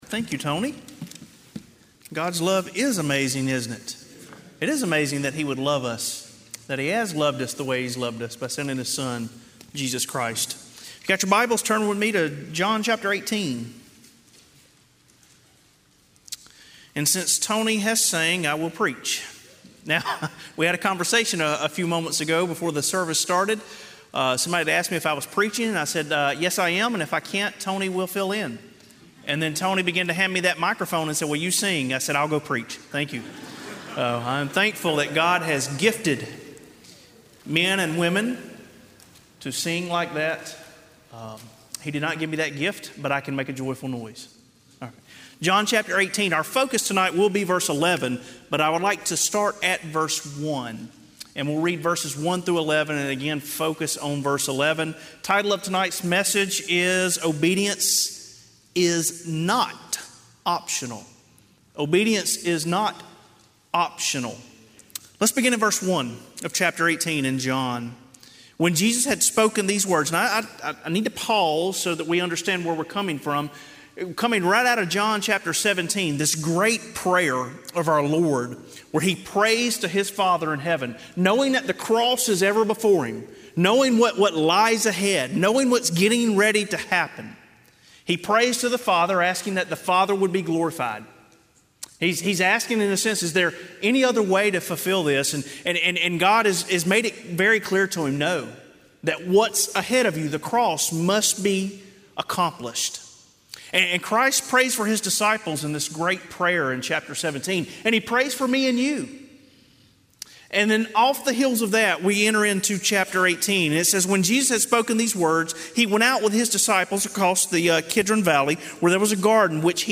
Sermon Audios/Videos - Tar Landing Baptist Church